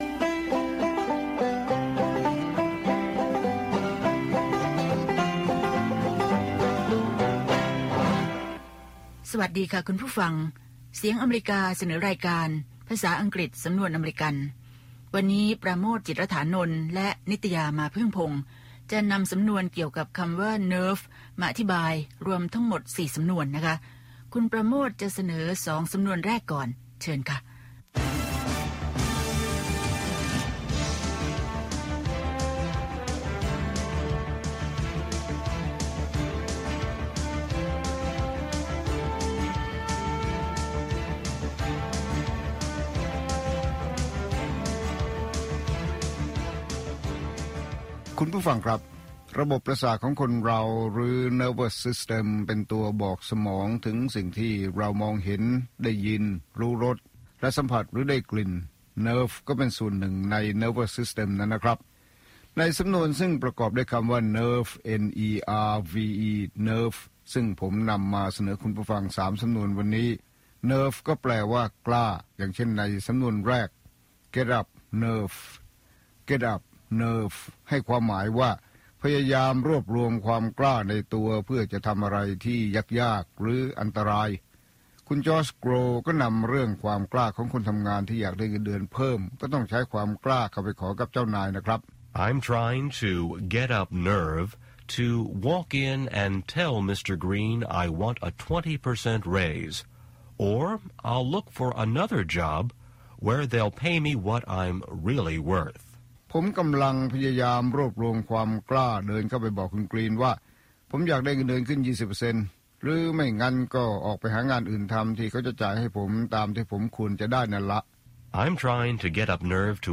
ภาษาอังกฤษสำนวนอเมริกัน สอนภาษาอังกฤษด้วยสำนวนที่คนอเมริกันใช้ มีตัวอย่างการใช้ และการออกเสียงจากผู้ใช้ภาษาโดยตรง